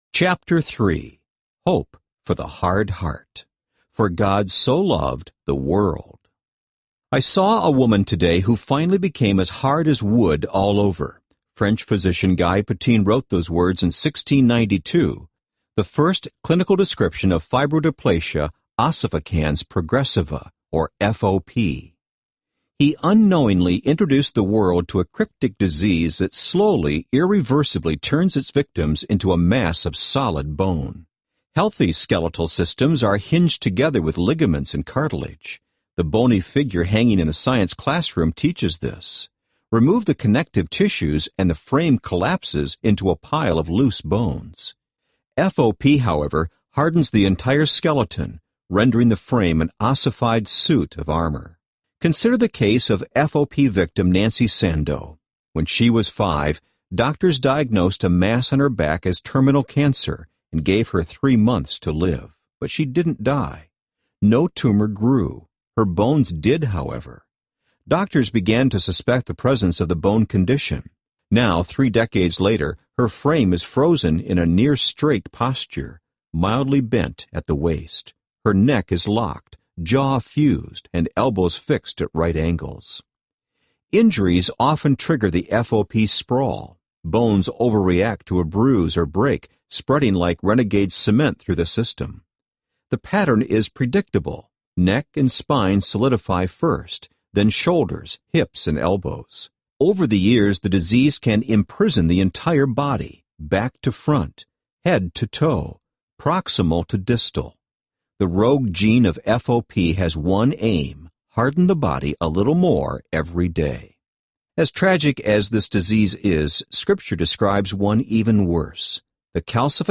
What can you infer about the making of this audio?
– Abridged